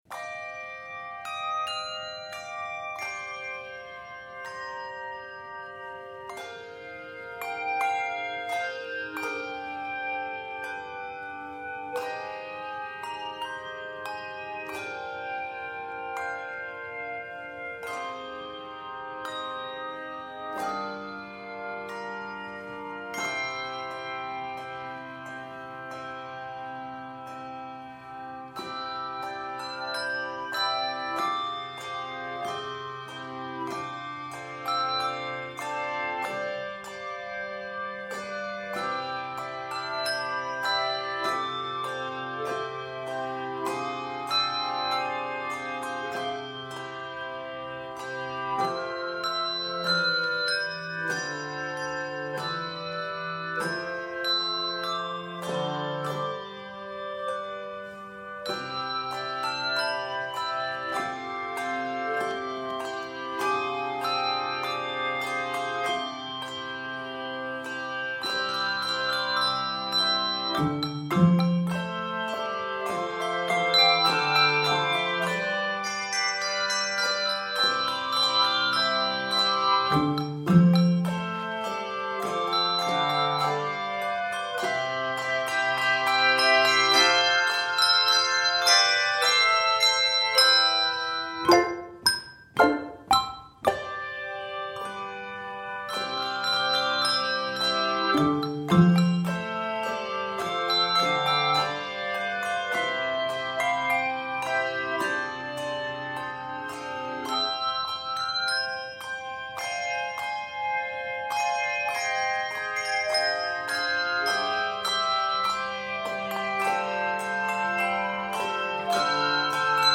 classic Christmas hymn tune